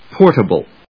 /pˈɔɚṭəbl(米国英語), pˈɔːṭəbl(英国英語)/